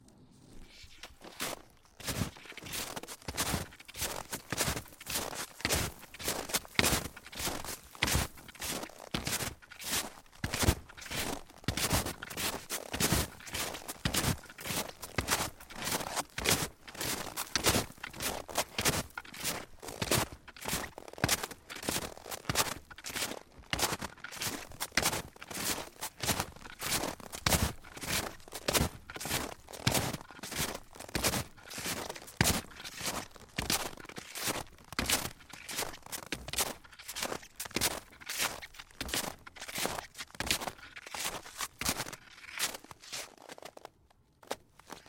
冬天 " 脚步声 雪鞋 老木头2 挤满了雪 走在小路上 嘎吱嘎吱的 软软的 废料
描述：脚步雪鞋老wood2包装雪步行下来clacky squeaky软scrapy.flac